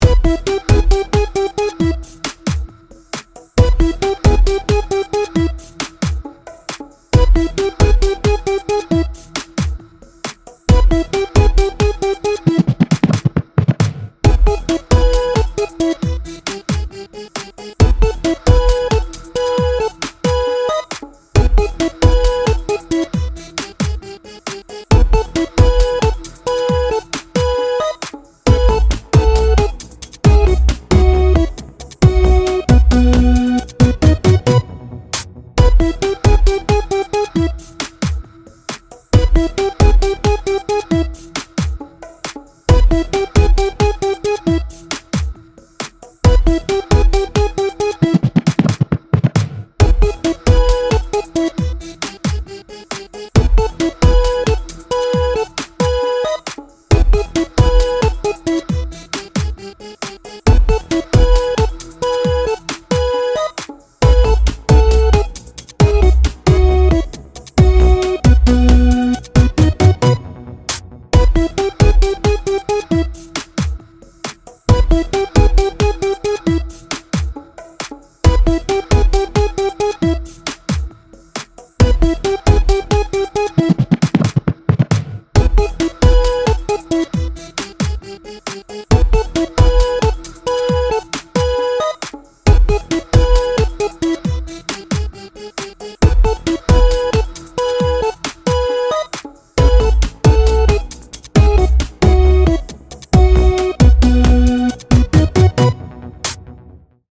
ゲームの準備中やVTuberなどの配信の待機画面にぴったりなBGMです。